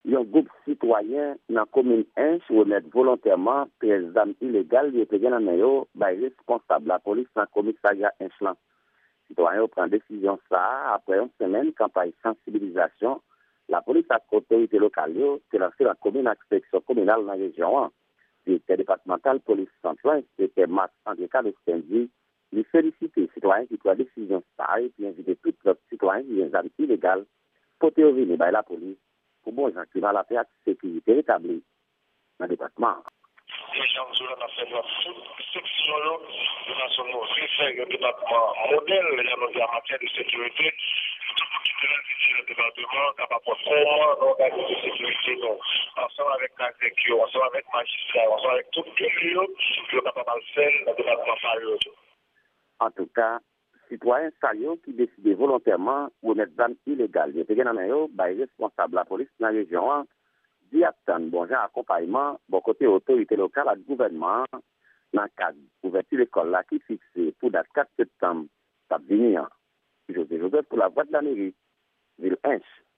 Yon repòtaj